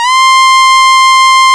STRS C5 F.wav